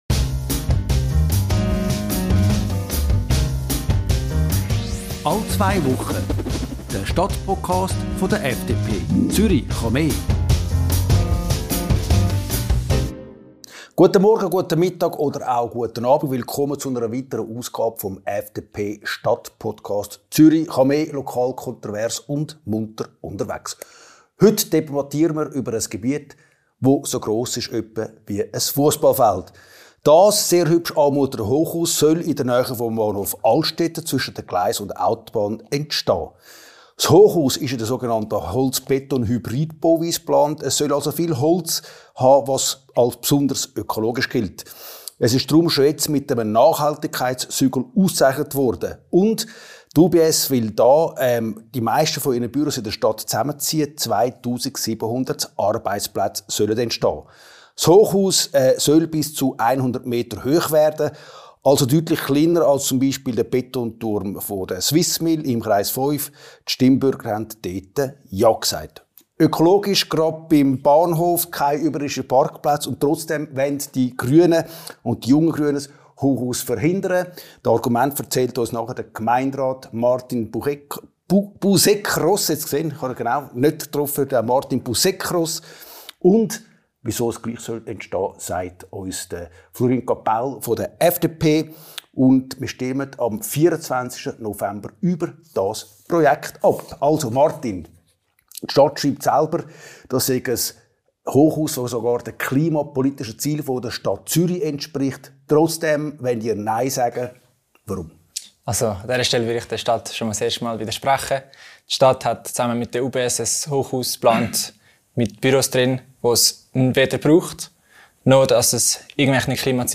FDP-Gemeinderat Flurin Capaul und der Grüne Gemeinderat Martin Busekros streiten im neusten Stadtpodcast über Ideologie und sinnvolles Bauen.